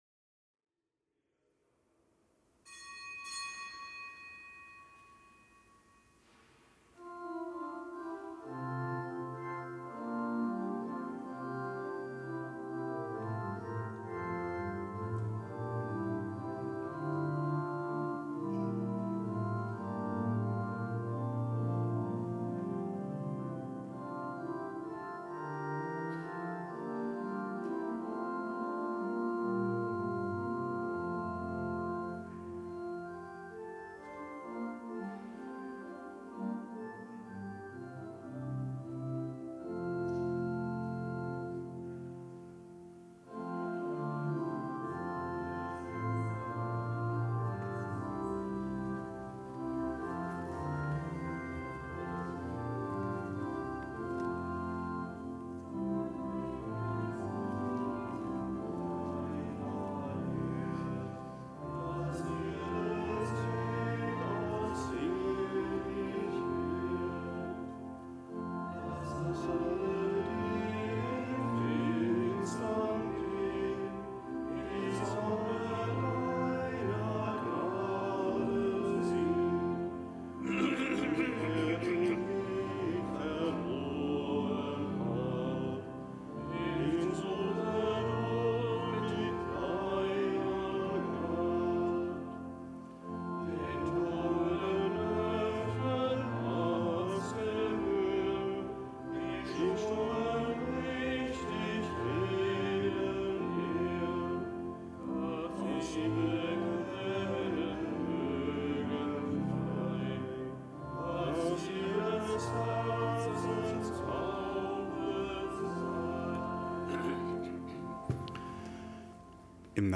Kapitelsmesse am Gedenktag des Heiligen Leo des Großen
Kapitelsmesse aus dem Kölner Dom am Gedenktag des Heiligen Leo des Großen, Papst und Kirchenlehrer